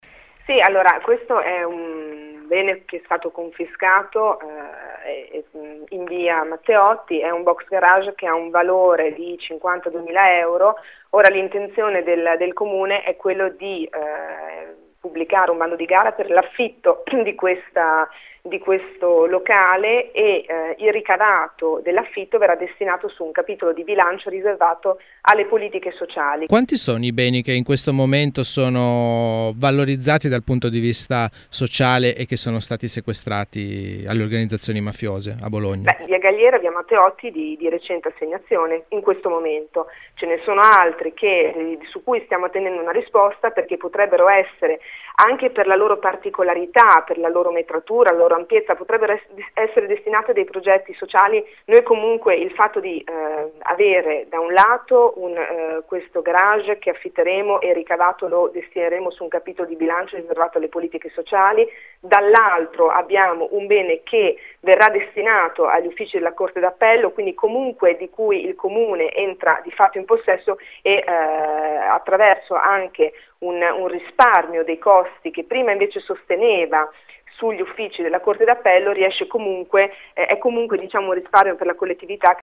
Ne abbiamo parlato con Nadia Monti, assessore alla legalità del Comune di Bologna.